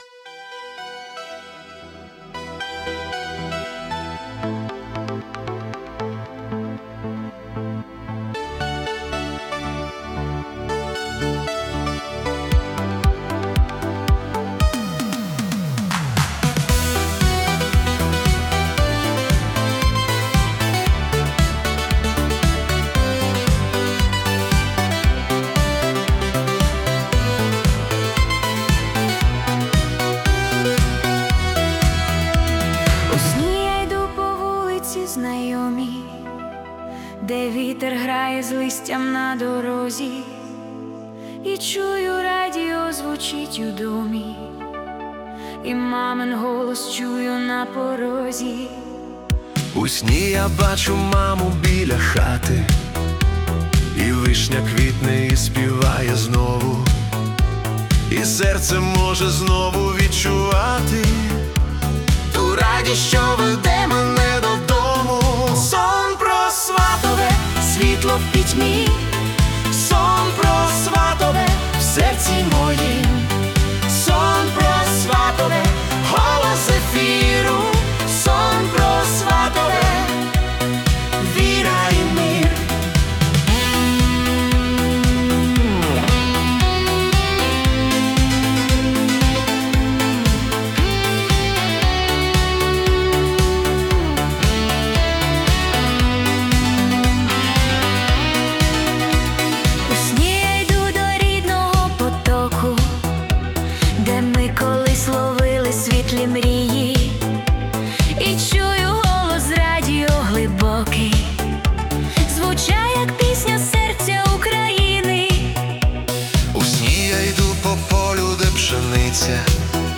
🎵 Жанр: Christian Italo Disco